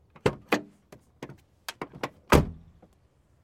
car door1